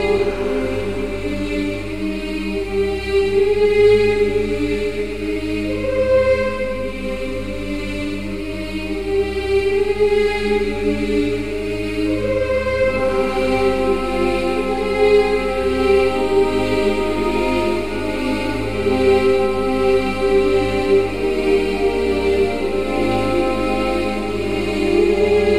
描述：唱诗班在唱着永恒的无聊的陷阱音符。）唱诗班循环在G MINOR; 希望你觉得这个有用。
标签： 150 bpm Trap Loops Choir Loops 4.31 MB wav Key : G
声道立体声